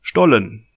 Stollen (German: [ˈʃtɔlən]